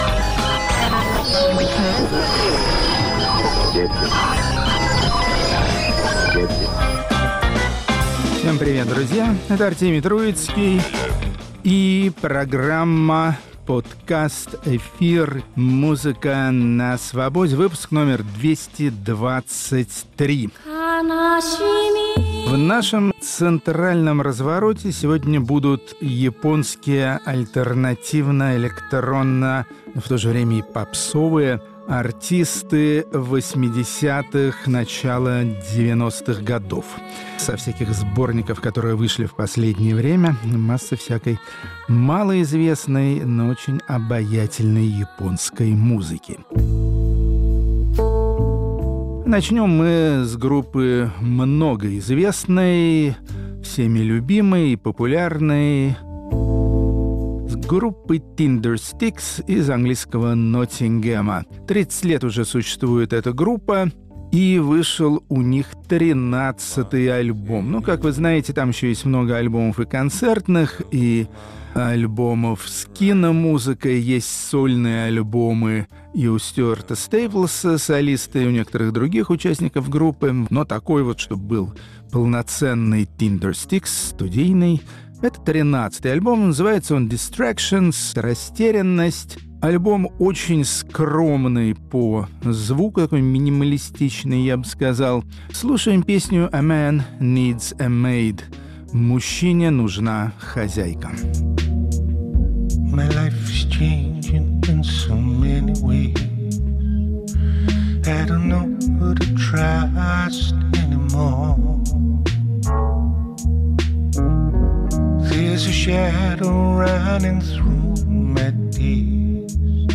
Музыка на Свободе. Восхождение на Фудзияму. Артемий Троицкий слушает сити-поп.